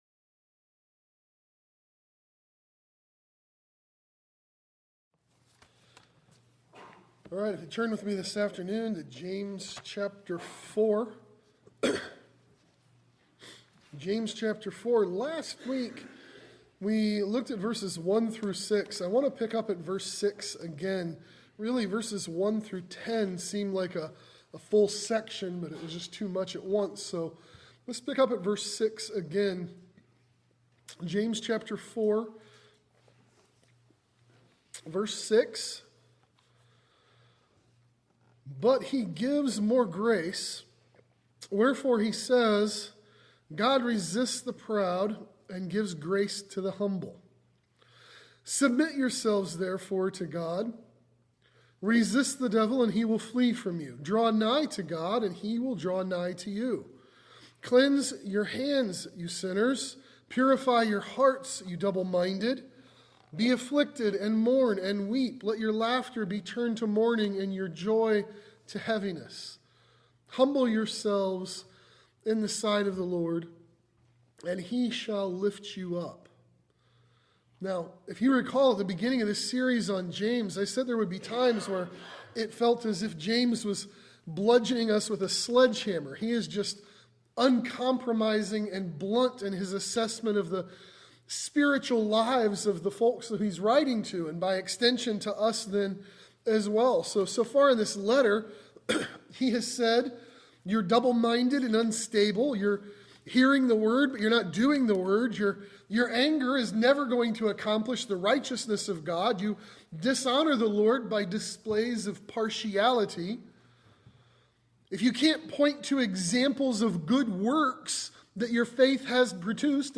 The Way Up Is Down | SermonAudio Broadcaster is Live View the Live Stream Share this sermon Disabled by adblocker Copy URL Copied!